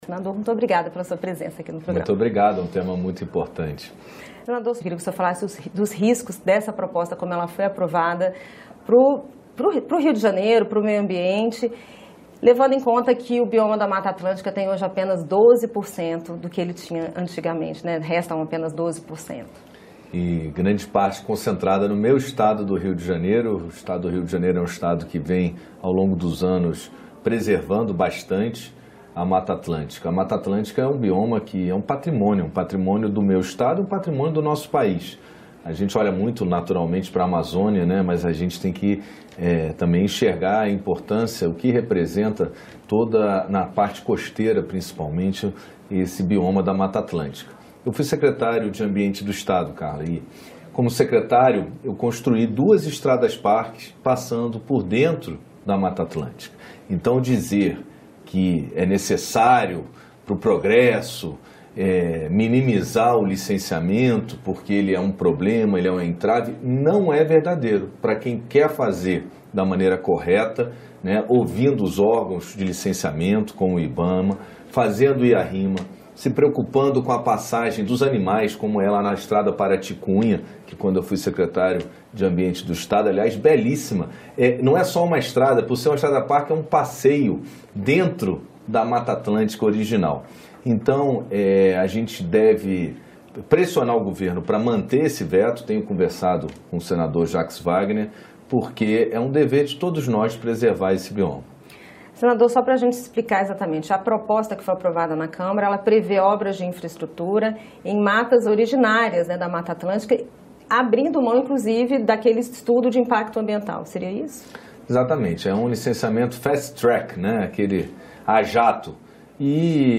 O senador Carlos Portinho (PL-RJ) faz o alerta, mas acredita ser possível investir em infraestrutura sem causar mais danos ao meio ambiente. Portinho defende o veto do presidente da República às mudanças na Lei. Acompanhe a entrevista do senador